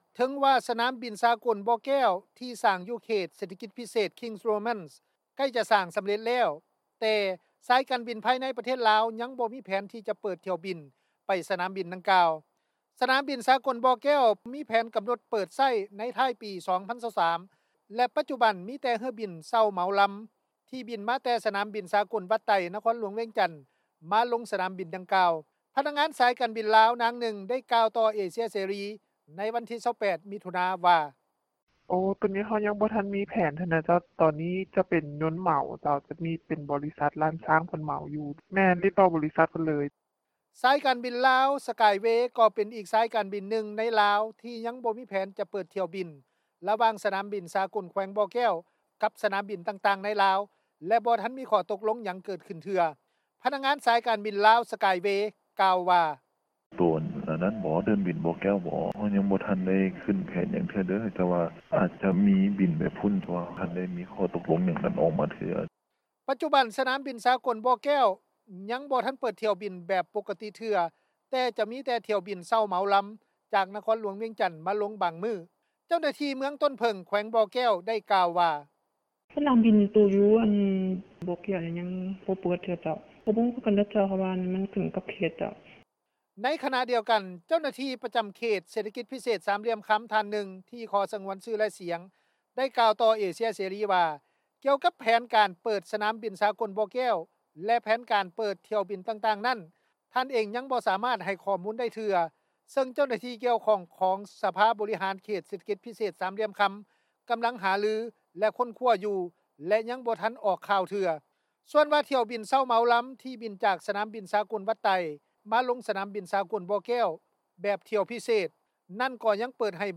ພະນັກງານ ສາຍການບິນລາວ ນາງນຶ່ງ ໄດ້ກ່າວຕໍ່ວິທຍຸ ເອເຊັຽ ເສຣີ ໃນວັນທີ 28 ມິຖຸນາ ວ່າ:
ພະນັກງານຂາຍປີ້ຍົນ ໄດ້ກ່າວວ່າ:
ຜູ້ປະກອບການ ດ້ານການທ່ອງທ່ຽວ ນາງນຶ່ງ ໄດ້ກ່າວວ່າ: